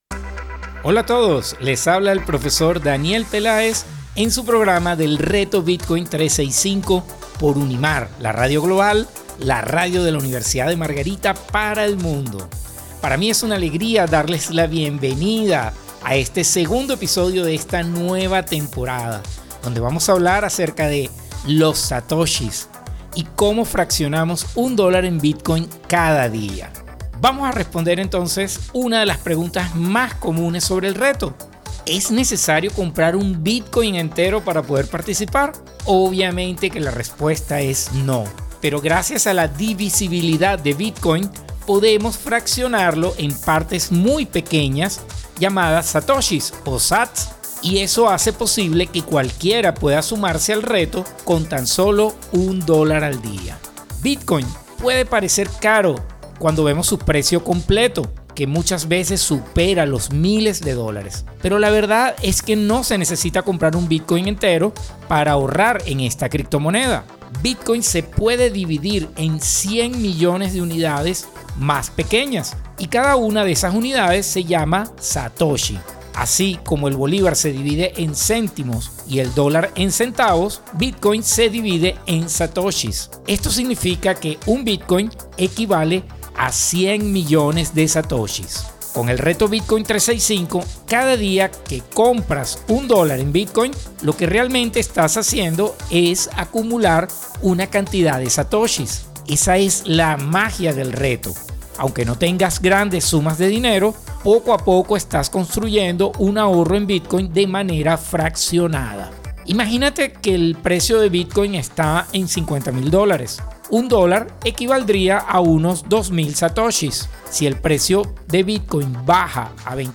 A través de entrevistas, testimonios y cápsulas informativas, los oyentes aprenderán estrategias prácticas para invertir de manera sostenida en Bitcoin utilizando el método de Dollar Cost Averaging (DCA).